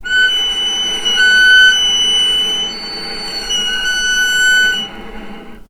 healing-soundscapes/Sound Banks/HSS_OP_Pack/Strings/cello/sul-ponticello/vc_sp-F#6-mf.AIF at cc6ab30615e60d4e43e538d957f445ea33b7fdfc
vc_sp-F#6-mf.AIF